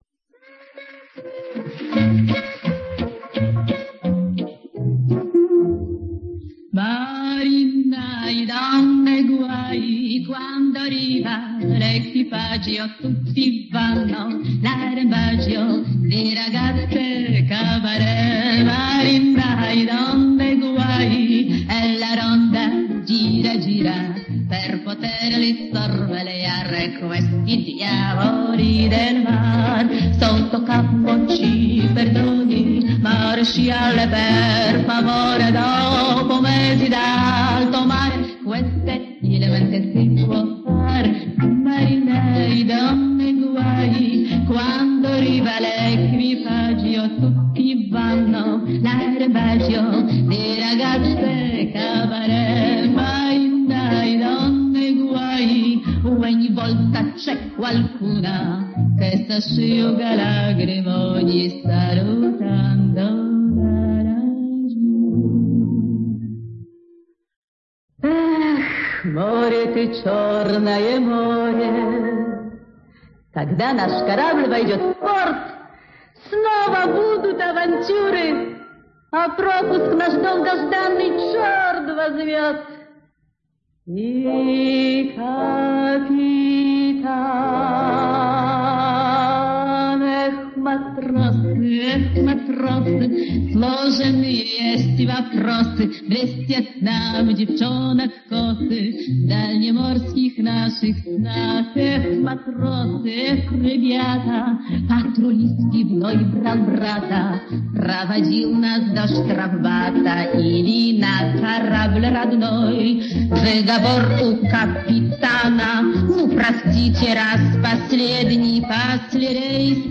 женский вокал (с явным акцентом).